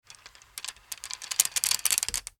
Кости на счетной доске собираются под действием гравитации